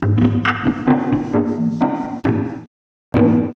Percussion 13.wav